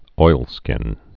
(oilskĭn)